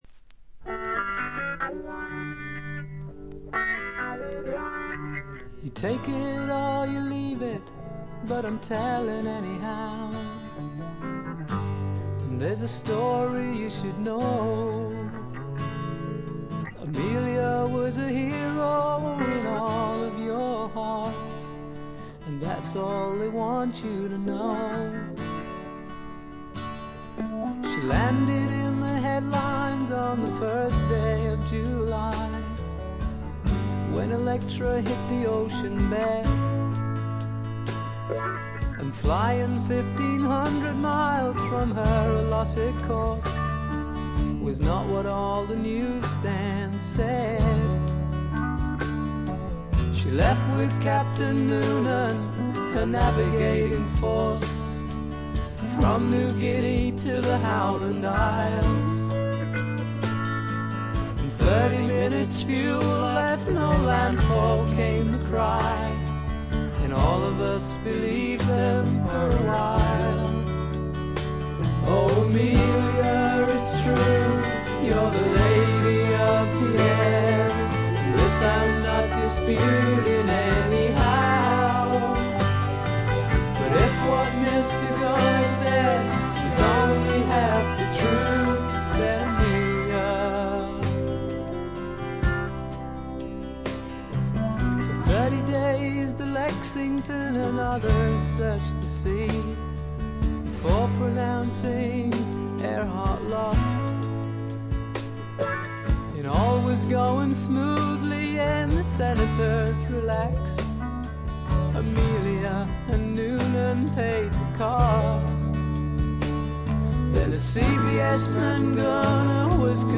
プカプカと牧歌的で枯れた味わいです。
mandolin, cello, mandola